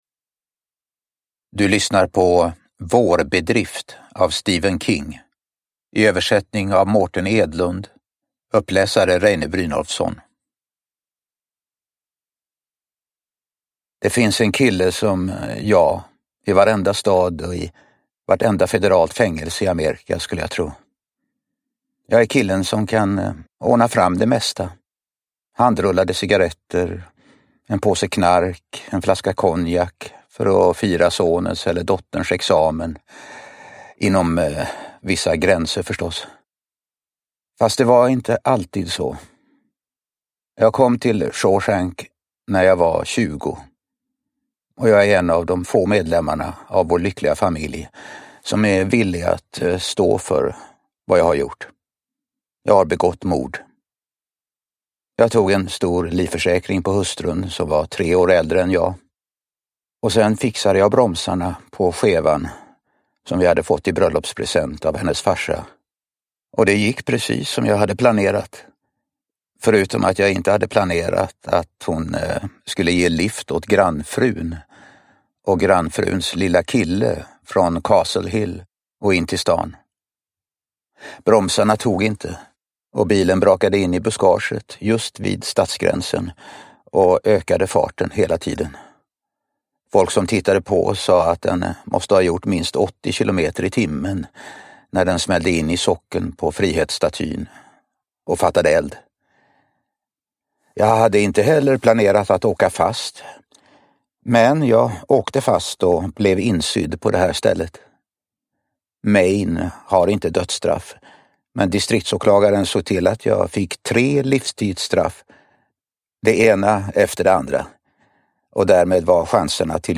Vårbedrift (Nyckeln till frihet). En av berättelserna ur novellsamlingen "Årstider" – Ljudbok
Uppläsare: Reine Brynolfsson